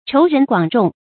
稠人廣眾 注音： ㄔㄡˊ ㄖㄣˊ ㄍㄨㄤˇ ㄓㄨㄙˋ 讀音讀法： 意思解釋： 稠：多而密。